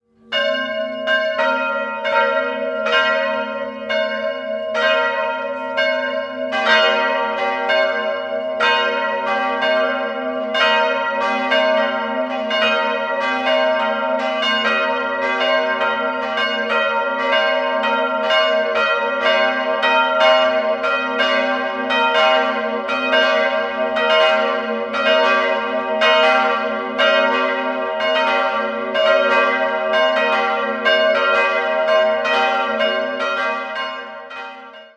Die größere Glocke ist historisch und wurde 1886 von Lothar Spannagl in Regensburg gegossen., bei den beiden kleineren handelt es sich um Eisenhartgussglocken der Firma Ulrich&Weule aus dem Jahr 1921.